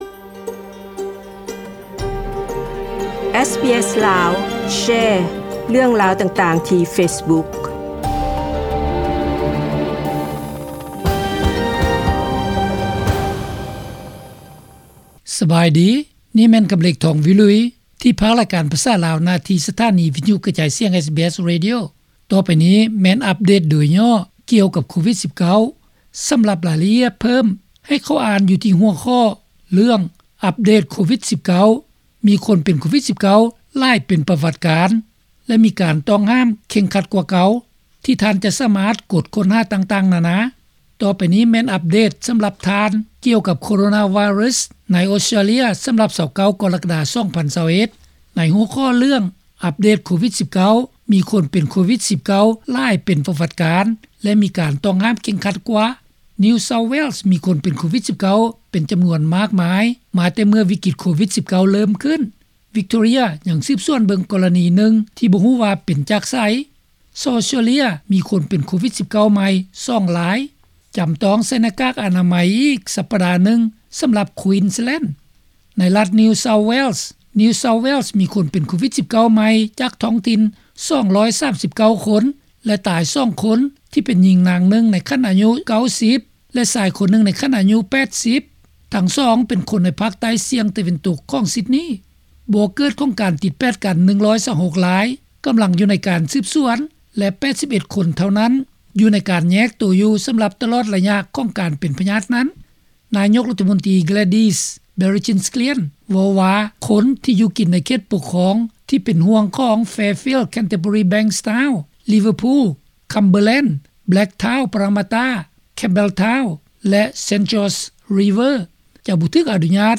ນີ້ແມ່ນບົດອ່ານກ່ຽວກັບການປັບໃຫ້ທັນການກ່ຽວັກບ ໂຄວິດ-19 ສຳຮັບ 29 ກໍຣະກະດາ 2021.